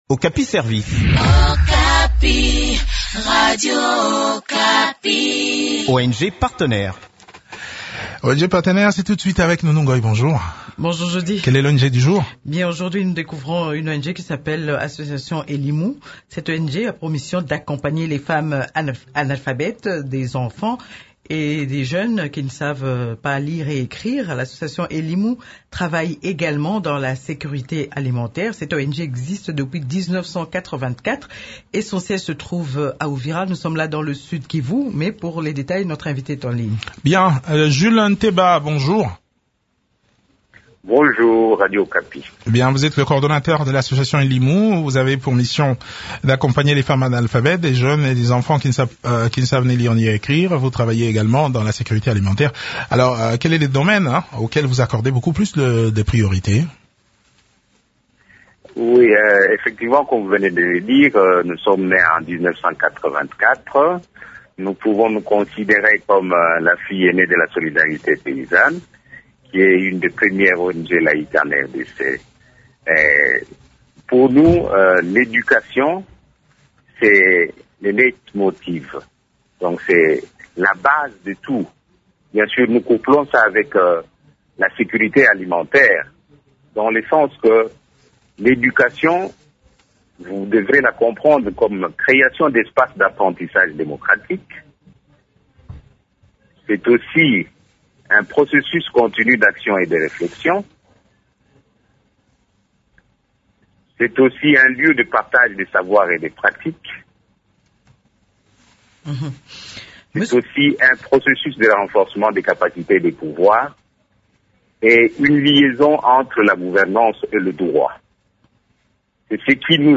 Le point des activités de cette structure dans cet entretien